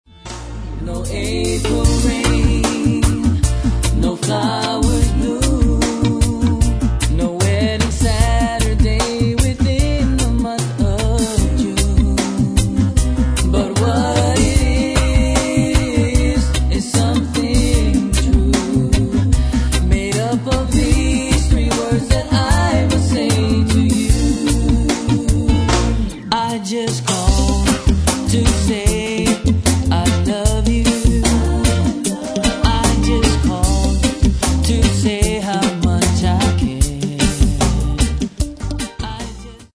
• Genre: Urban, world music, island rhythms.